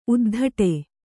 ♪ uddhaṭe